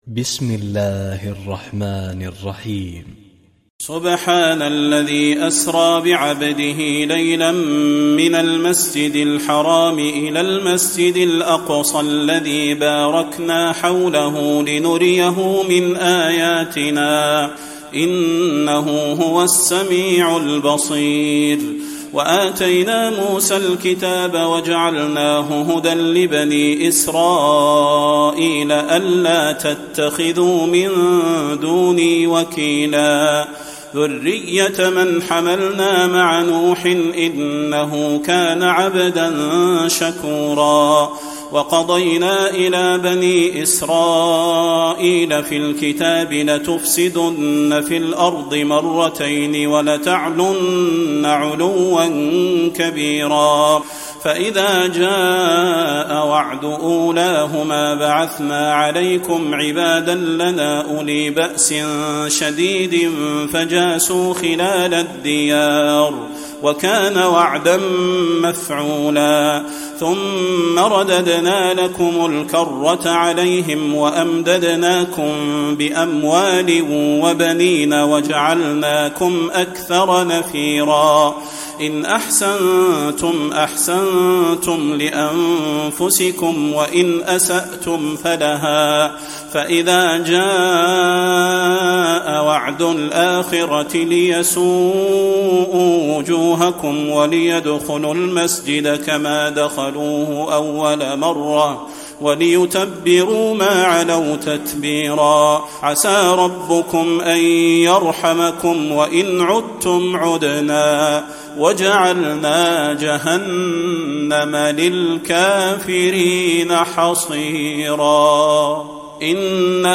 تراويح الليلة الرابعة عشر رمضان 1435هـ من سورة الإسراء (1-98) Taraweeh 14 st night Ramadan 1435H from Surah Al-Israa > تراويح الحرم النبوي عام 1435 🕌 > التراويح - تلاوات الحرمين